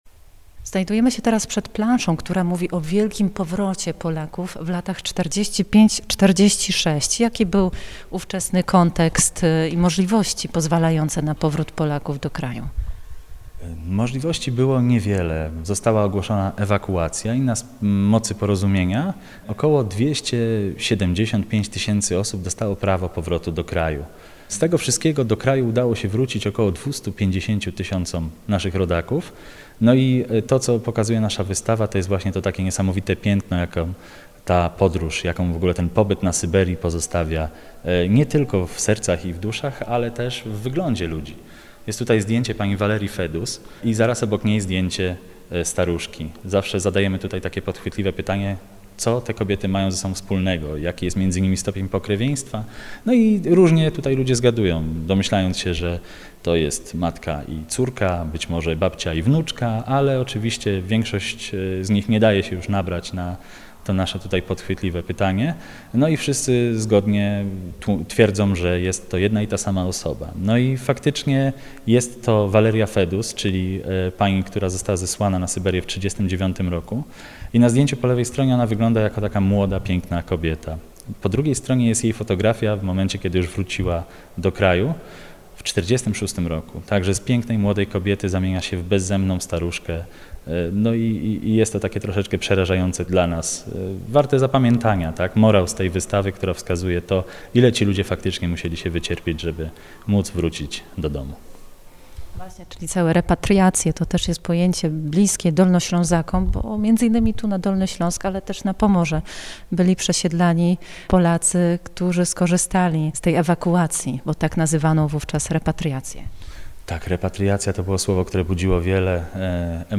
Rozmowa o przesiedleniach i powrotach w piątek 14 czerwca po godz. 14:00.